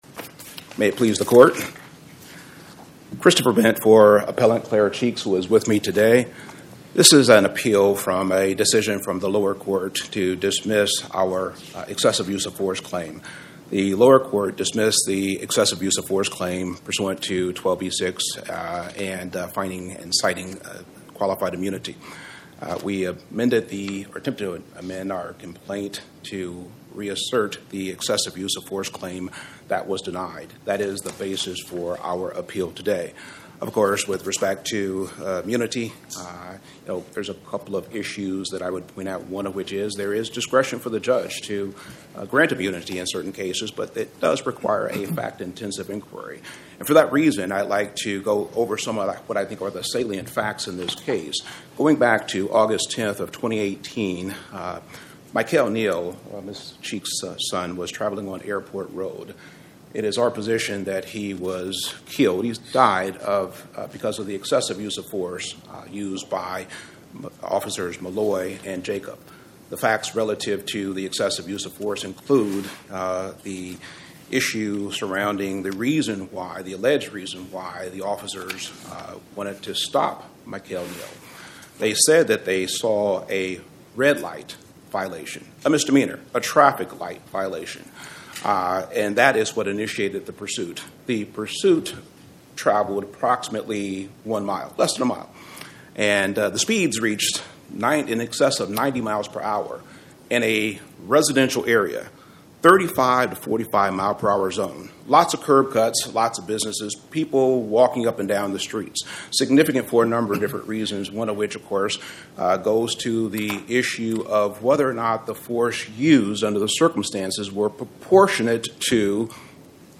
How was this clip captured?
Oral argument argued before the Eighth Circuit U.S. Court of Appeals on or about 09/16/2025